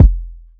Going To Sleep Kick.wav